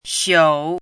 chinese-voice - 汉字语音库
xiu3.mp3